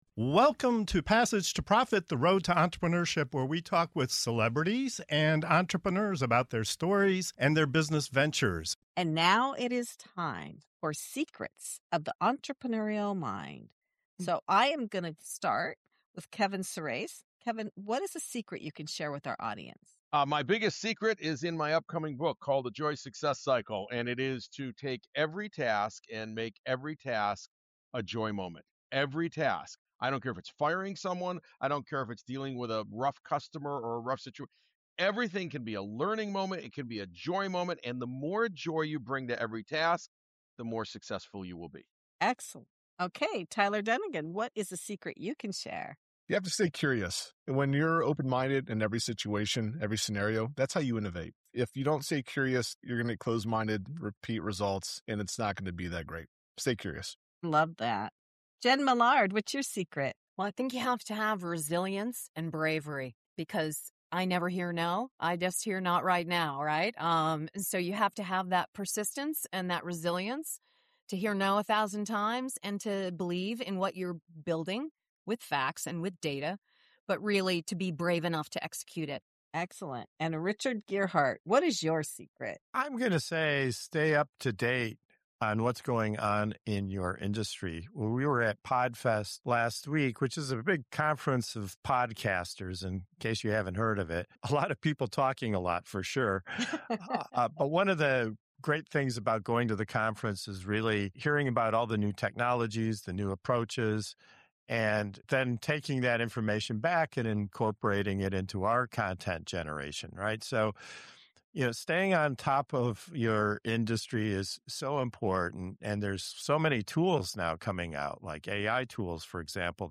What if the real secret to entrepreneurial success isn’t hustle—but mindset? In this fast-paced segment of "Secrets of the Entrepreneurial Mind" on Passage to Profit Show, top founders share powerful insights on turning every task—even the tough ones—into moments of joy, staying relentlessly curious to spark innovation, hearing “no” as “not right now,” and leveraging AI and new technologies to stay competitive.